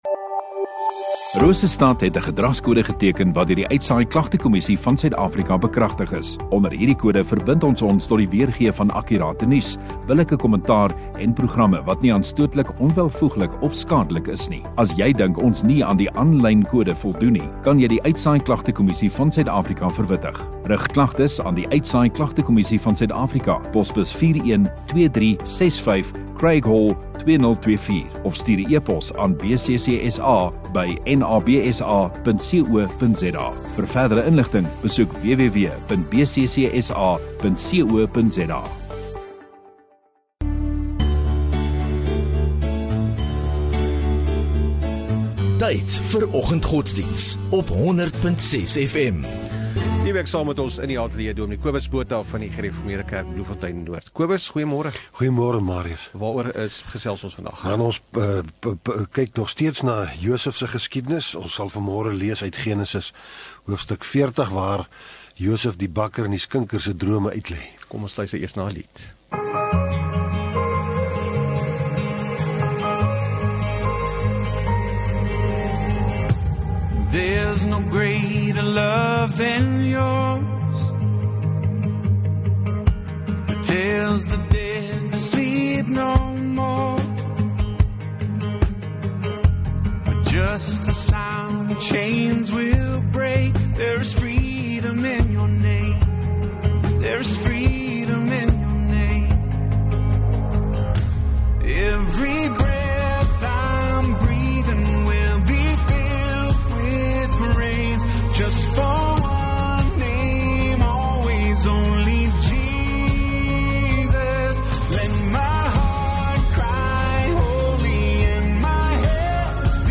11 Sep Woensdag Oggenddiens